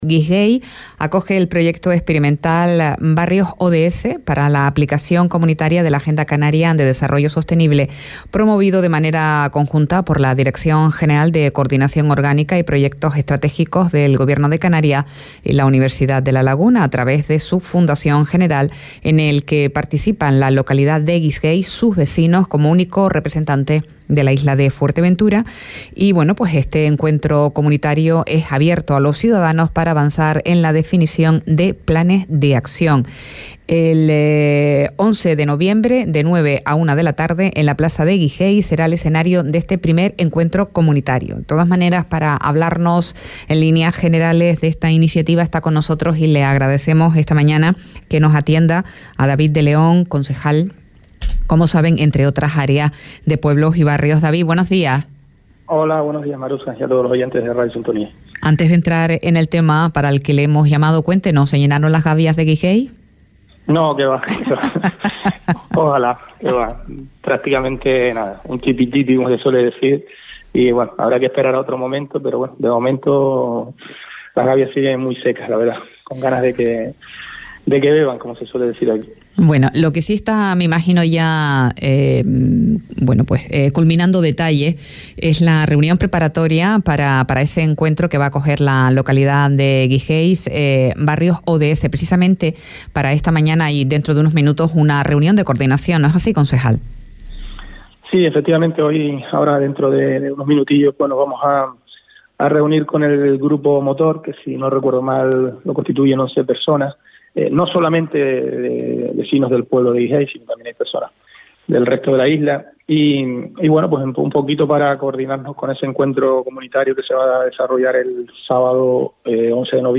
David de León, concejal de Barrios y Pueblos de Puerto del Rosario nos explica en ‘A primera hora’ las actividades previstas en la localidad de Guisguey dentro de la programación de el proyecto experimental que ha puesto en marcha el Gobierno de Canarias, a través de la Dirección General de Investigación y Coordinación del Desarrollo […]
Entrevistas